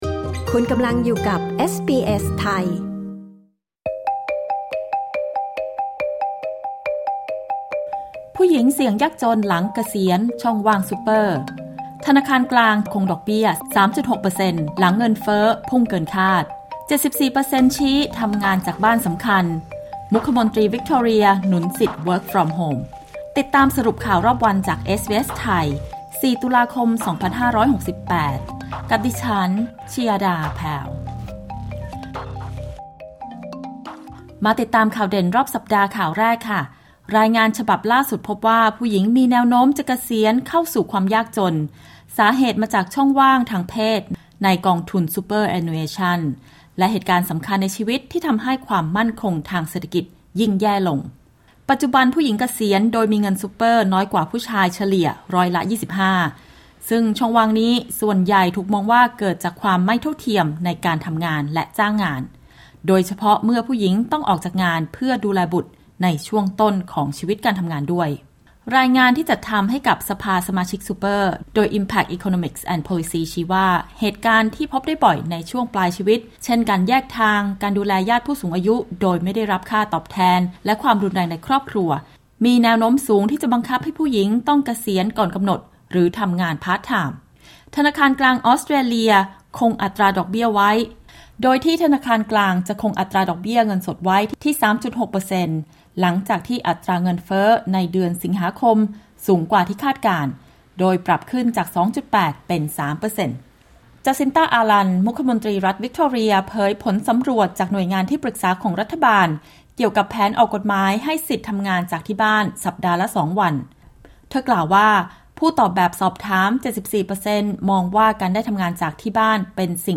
สรุปข่าวรอบสัปดาห์ 4 ตุลาคม 2568